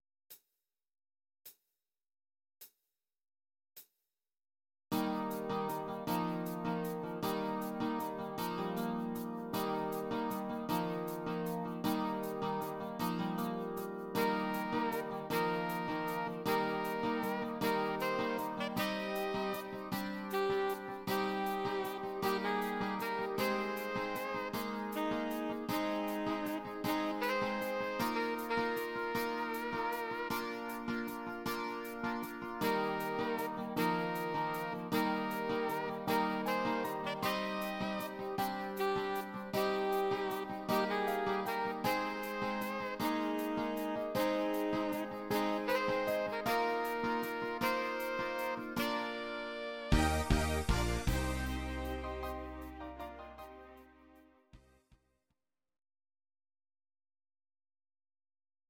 Audio Recordings based on Midi-files
Pop, Ital/French/Span, 1980s